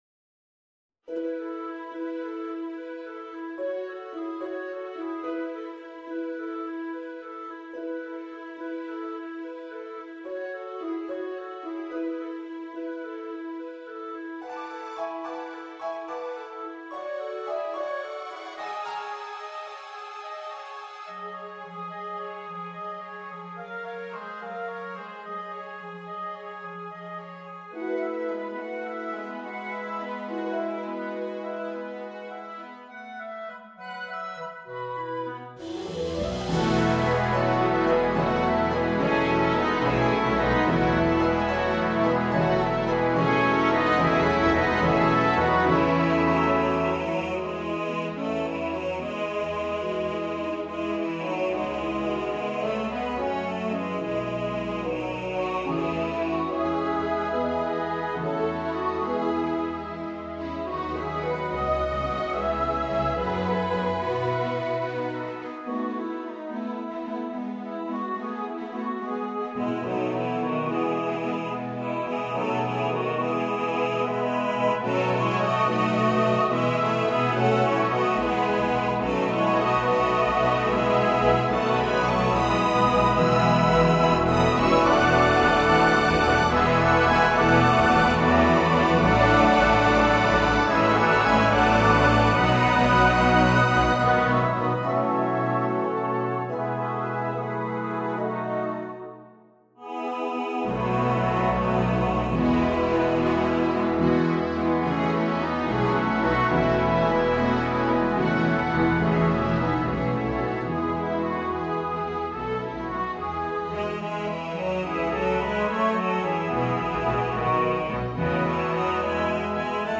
This is a piano reduction.
Voicing/Instrumentation: SATB , Piano Solo